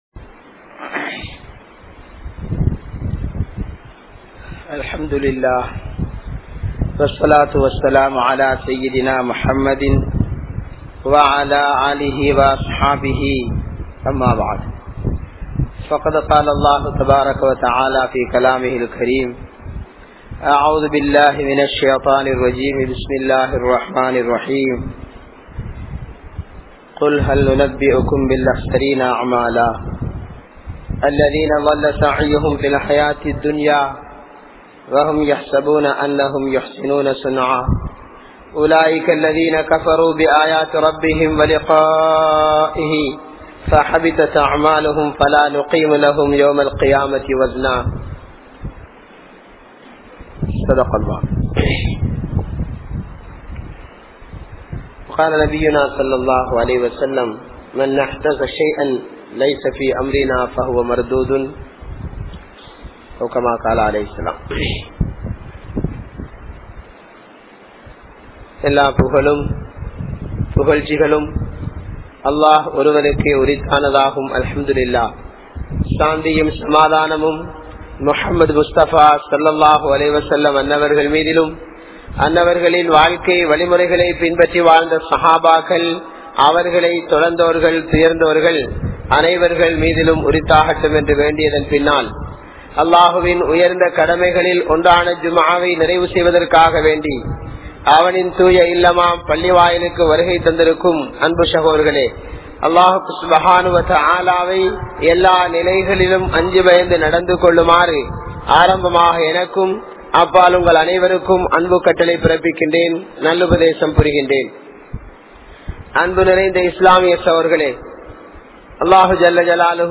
Seeralium Indraya Thirumanagal (சீிரழியும் இன்றைய திருமணங்கள்) | Audio Bayans | All Ceylon Muslim Youth Community | Addalaichenai